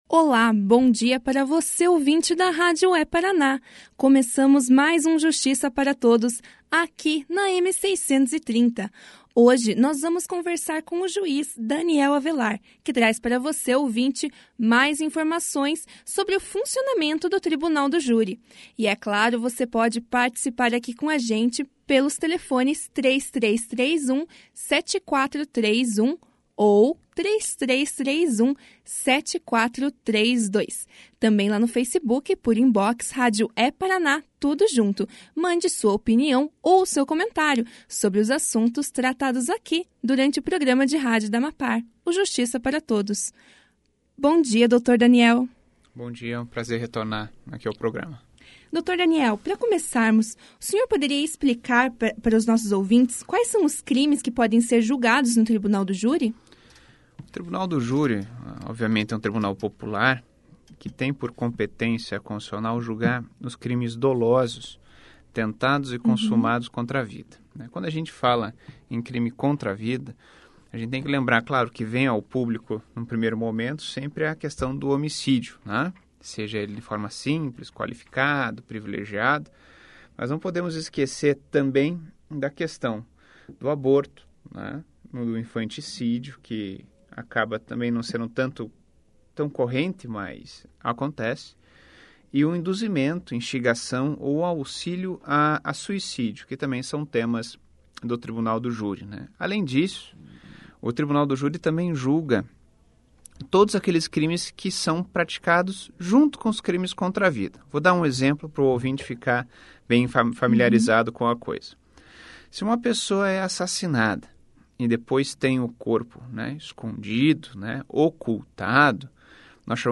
O juiz da 2ª Vara do Tribunal do Júri, Daniel Avelar, participou do programa de rádio da AMAPAR de hoje (23) e falou aos ouvintes sobre o funcionamento do Tribunal do Júri. Durante a entrevista ele explicou sobre os crimes que podem ser julgados no Júri, as etapas do julgamento e sobre a escolha dos jurados. Além disso, Avelar comentou a respeito dos critérios para o juiz para estipular a pena.